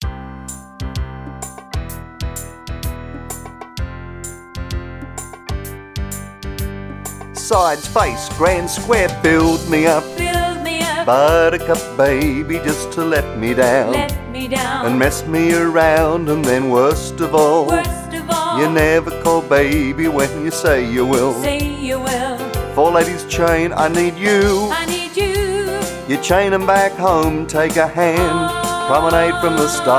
Instrumental
Vocal